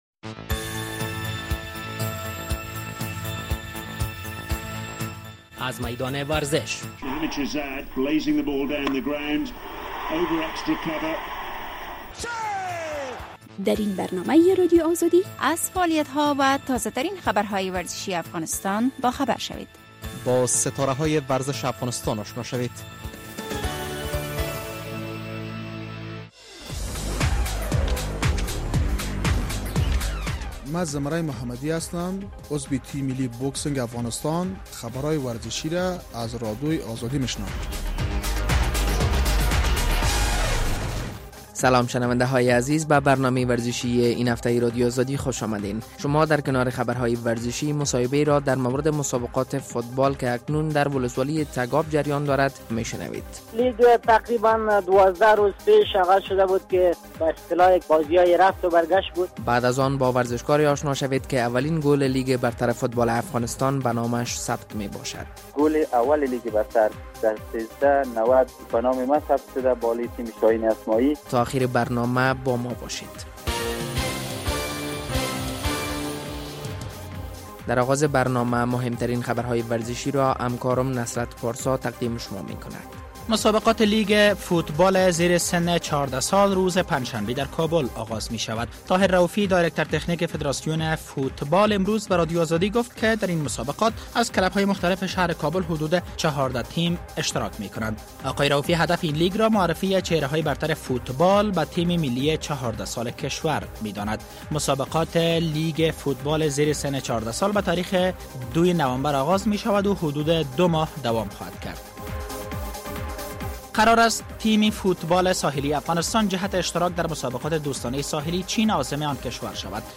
در برنامۀ ورزشی این هفته ما در کنار خبرهای ورزشی مصاحبه را در مورد لیگ فوتبال در ولسوالی تگاب کاپیسا را می‌شنوید.